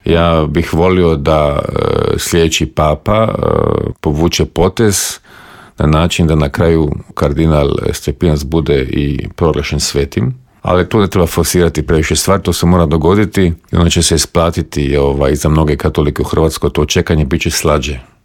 ZAGREB - Aktualne teme s naglaskom na početak konklave, izbor novog pape, ratne sukobe i situaciju u susjedstvu, prokomentirali smo u Intervjuu Media servisa s diplomatom i bivšim ministarom vanjskih poslova Mirom Kovačem.